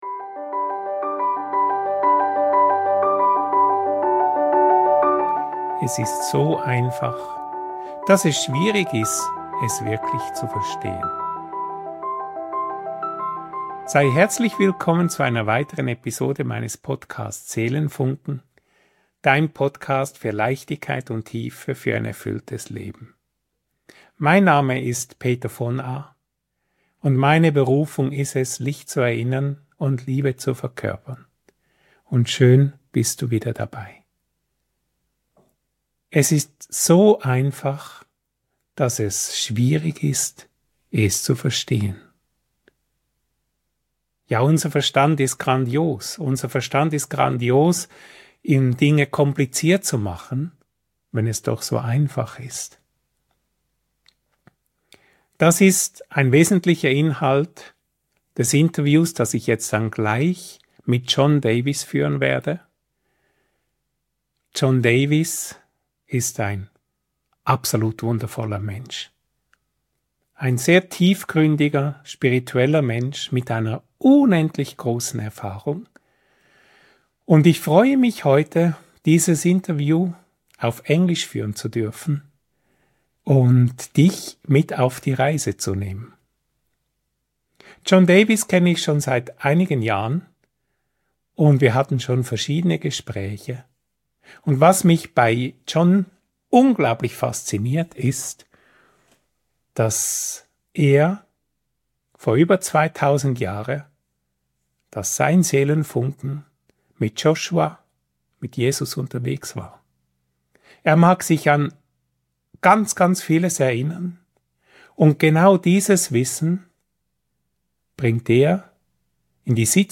In dieser Episode habe ich einen wirklich besonderen Gast an meiner Seite